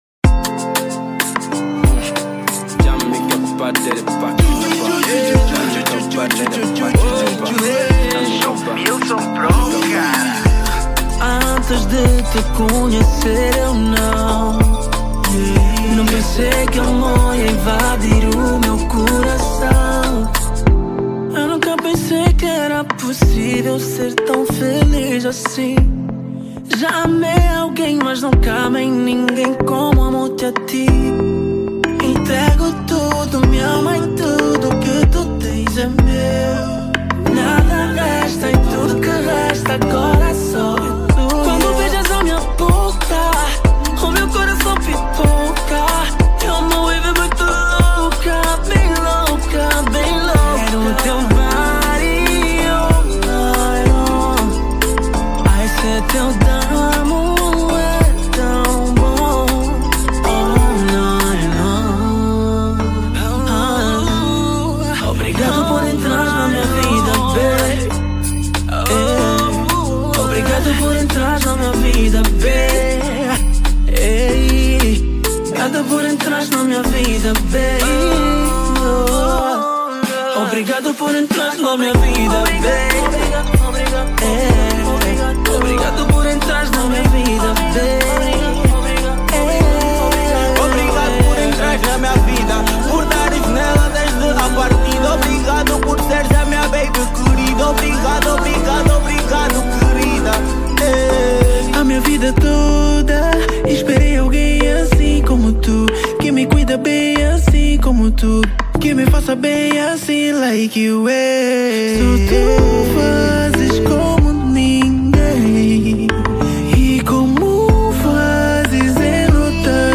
Categoria: Kizomba